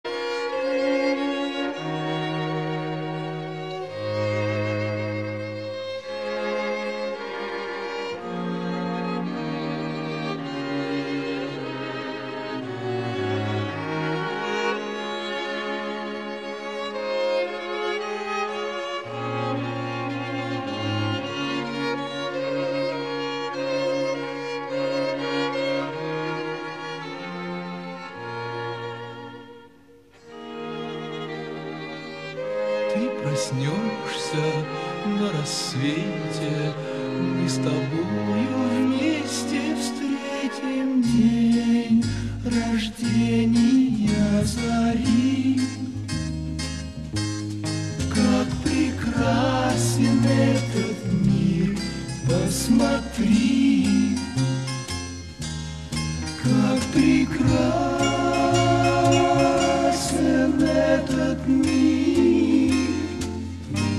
Звучит мелодия без слов